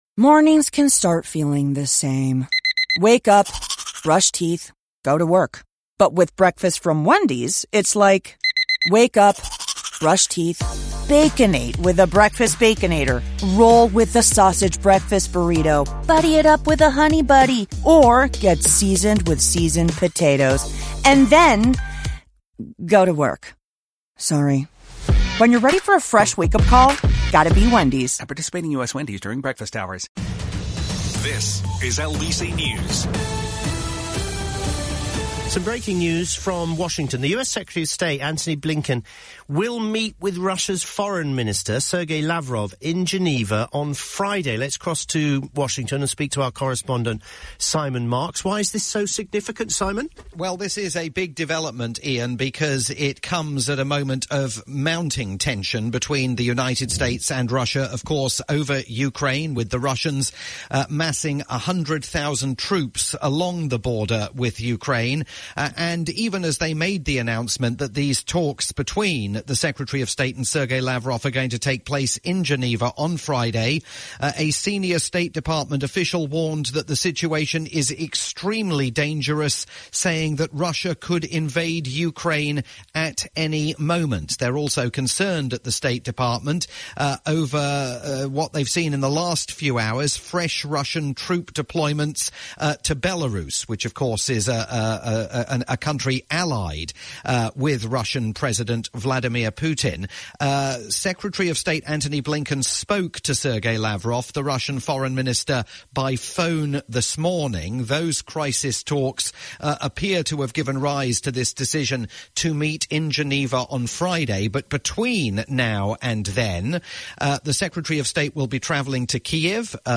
breaking news report